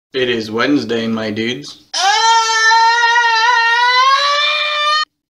meme